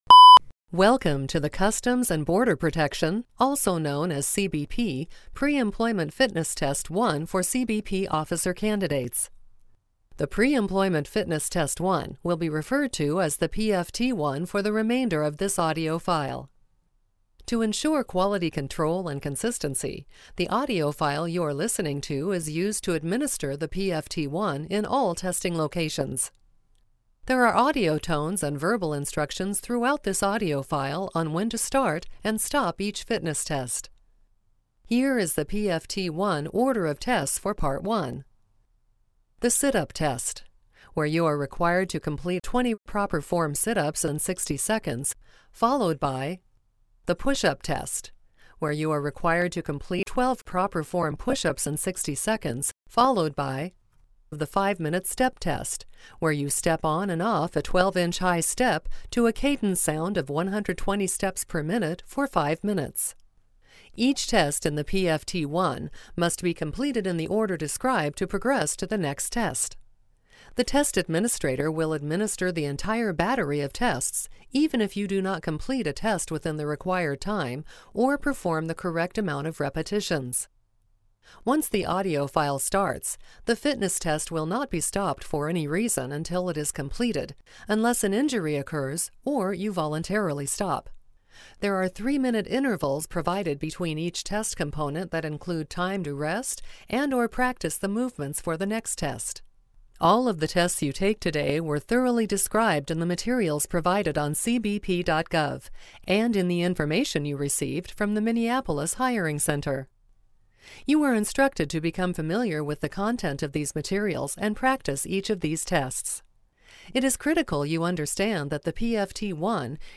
Customs and Border Protection Officer Narrated Preemployment Fitness Test - Test Administrator Version